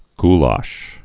(gläsh, -lăsh)